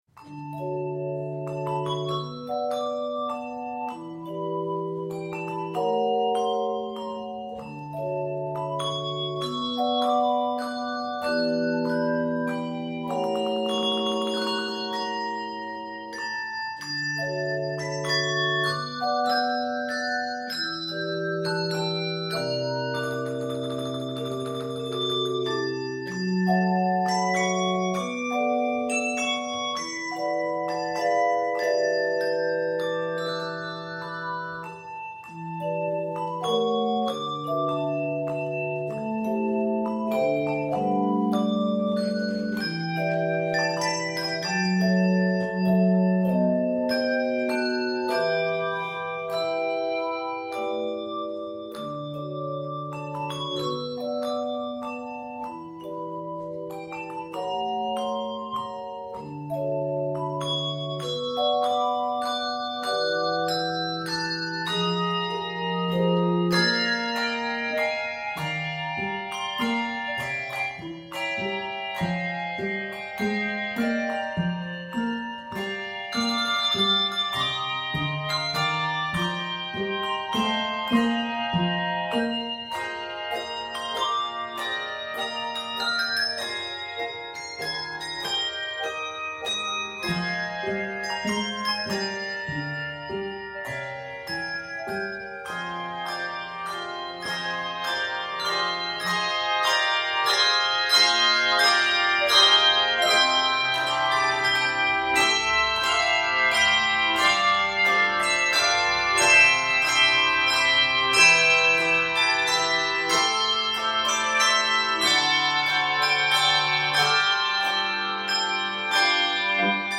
Set in a gentle jazz waltz